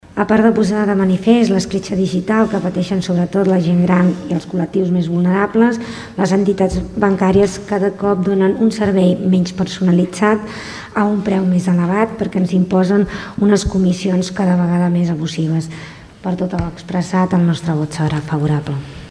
El Ple de l’Ajuntament de Tordera va aprovar una moció per controlar la digitalització dels serveis bancaris.
Des del PSC, Toñi Garcia posava en manifest l’escletxa digital entre les diferents generacions.